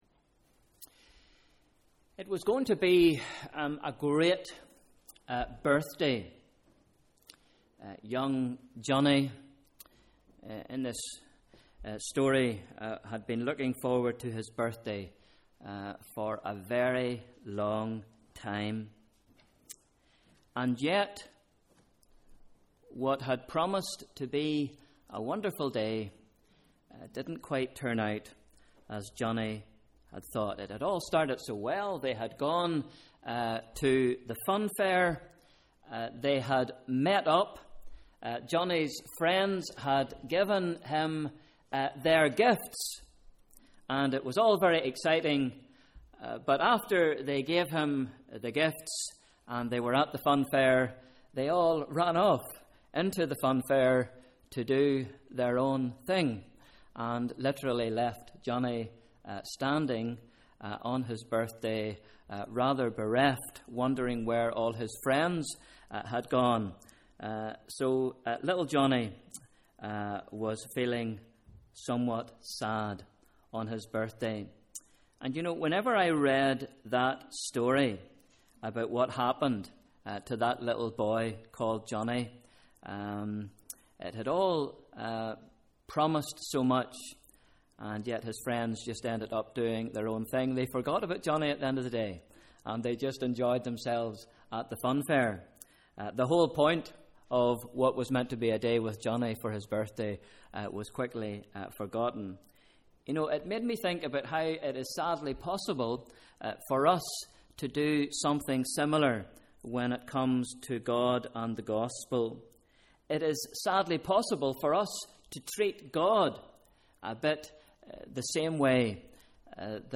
Bible Reading: John 4 v 19-26 Sunday 13th October: Evening Service